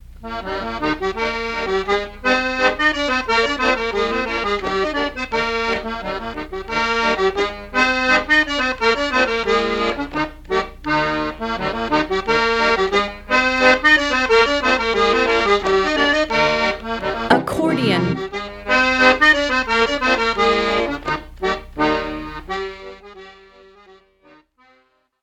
acordeon.mp3